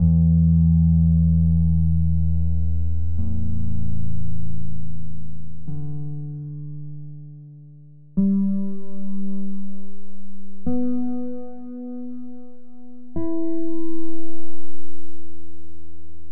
In figura, i campioni prodotti dalla sintesi.